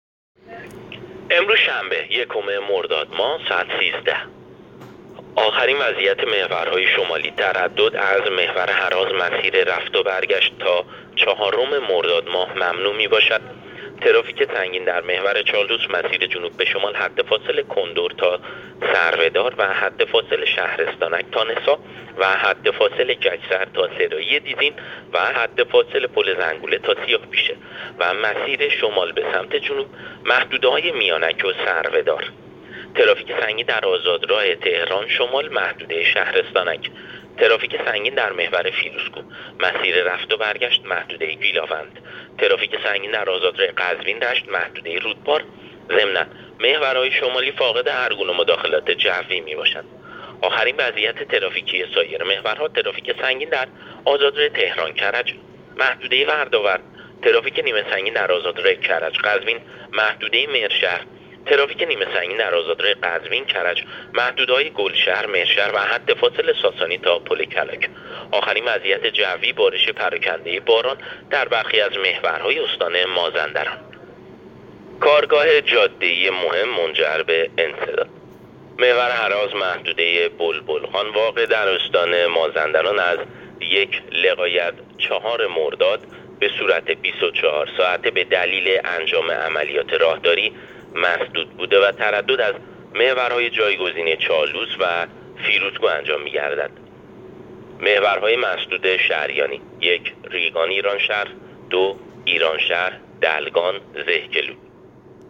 گزارش رادیو اینترنتی از آخرین وضعیت ترافیکی جاده‌ها تا ساعت ۱۳ یکم مرداد ماه؛